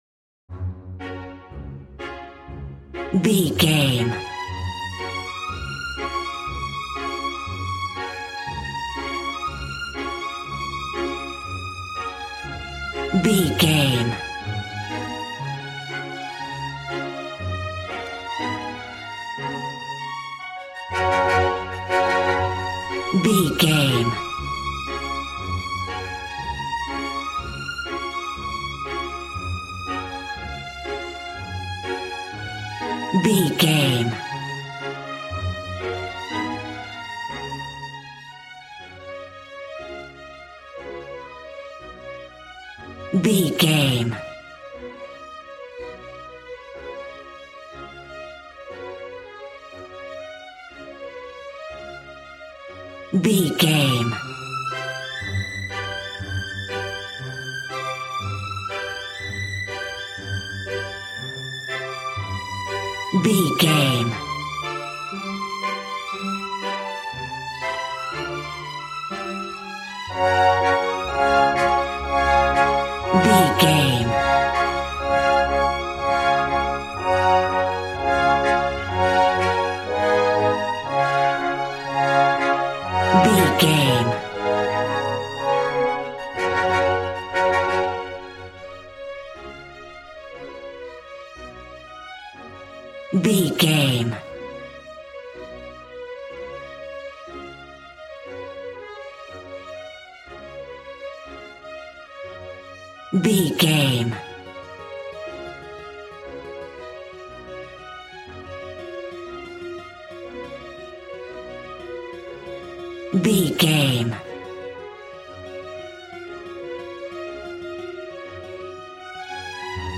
Ionian/Major
D♭
cheerful/happy
joyful
drums
acoustic guitar